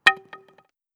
SFX_Wood_Knock.wav